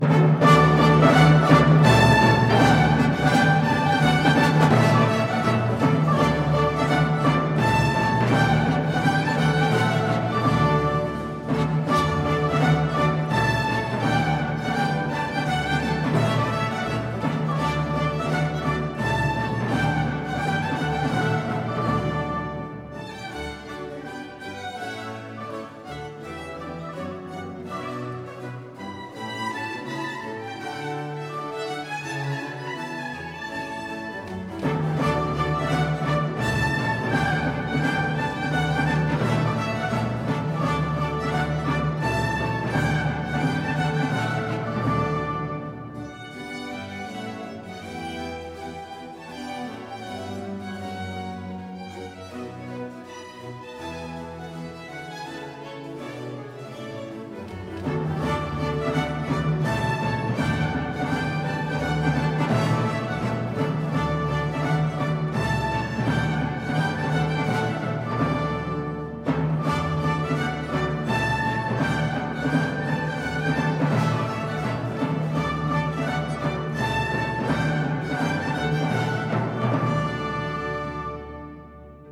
..ist ein Instrumentalensemble für Alte Musik in Koblenz.
Sie spielen auf Originalinstrumenten der jeweiligen Zeit oder auf originalgetreuen Nachbauten.